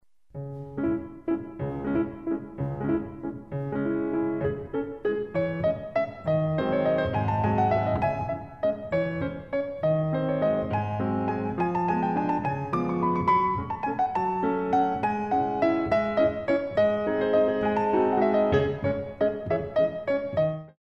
4x8 - 6/8